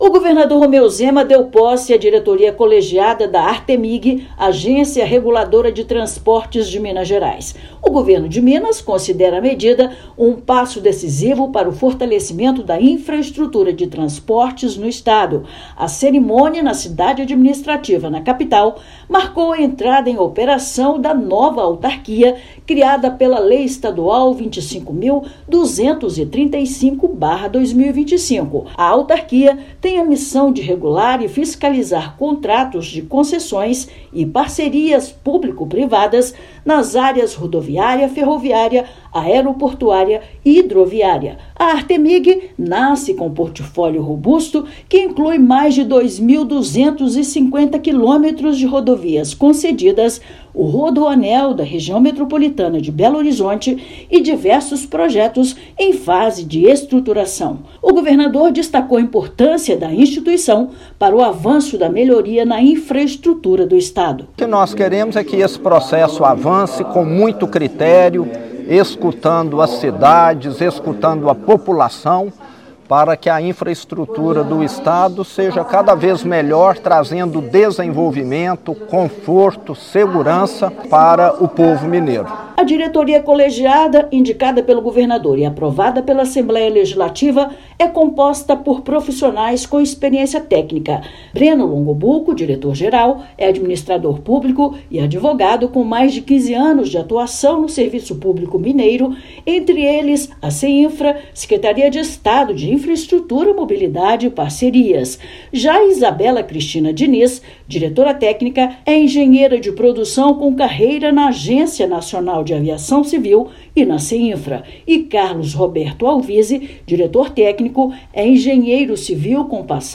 [RÁDIO] Governo de Minas oficializa posse da diretoria da Artemig e consolida marco regulatório para transportes no estado
Nova autarquia inicia operação com foco em segurança jurídica e qualidade nos serviços de concessões e PPPs do Estado. Ouça matéria de rádio.